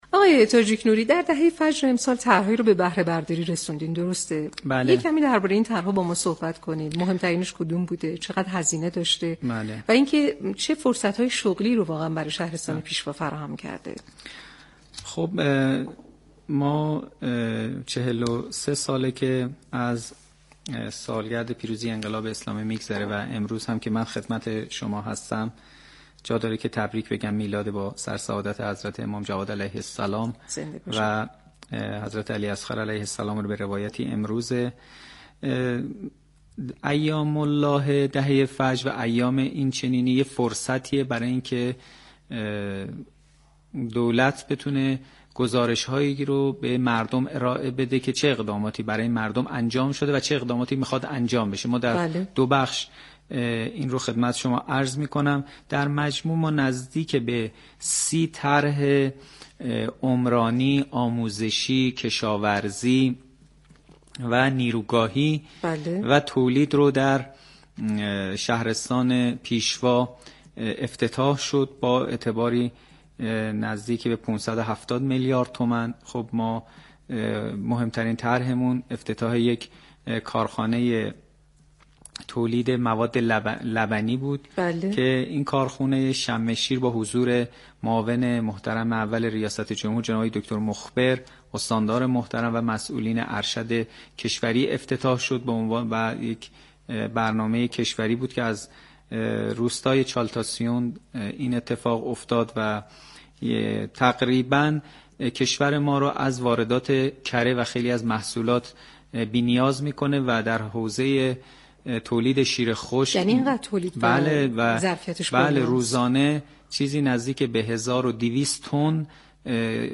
به گزارش پایگاه اطلاع رسانی رادیو تهران، ابراهیم تاجیك نوری فرماندار شهرستان پیشوا در گفتگو با برنامه پل مدیریت 23 بهمن در خصوص افتتاح و بهره برداری از طرح‌های این شهرستان در حوزه‌های مختلف گفت: ایام الله دهه فجر فرصتی است برای اینكه دولت بتواند از اقدامات انجام شده و اقداماتی كه در دست اقدام است گزارشی را به مردم ارائه دهد.